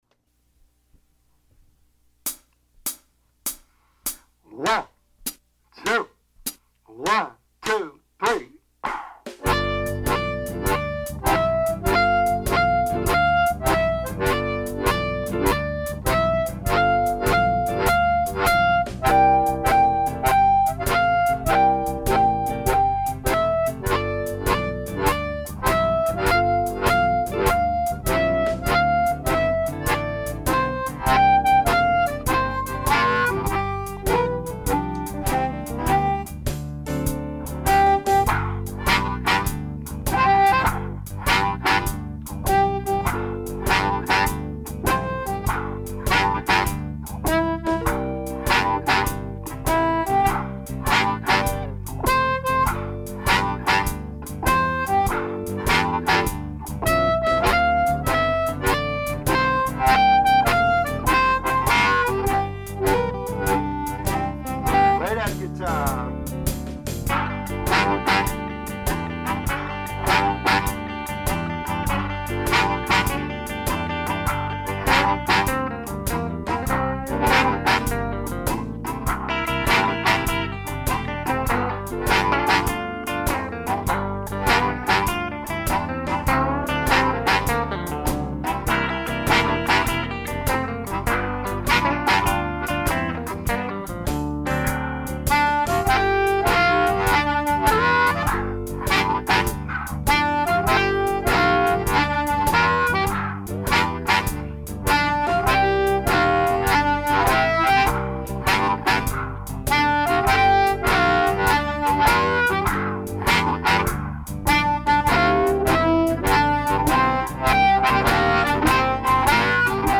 All Amplified 100bpm